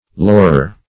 laurer - definition of laurer - synonyms, pronunciation, spelling from Free Dictionary
laurer - definition of laurer - synonyms, pronunciation, spelling from Free Dictionary Search Result for " laurer" : The Collaborative International Dictionary of English v.0.48: Laurer \Lau"rer\, n. Laurel.